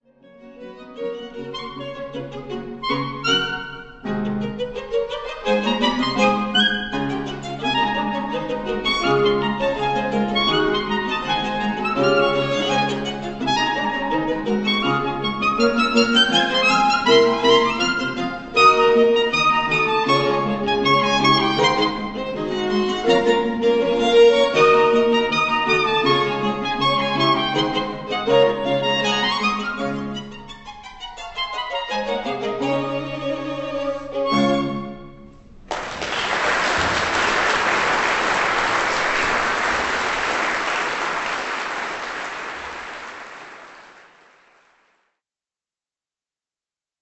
* Quartett mit G-Klarinette
Aufgenommen live am 13.5.2007,